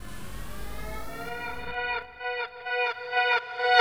5th Switch Reversed.wav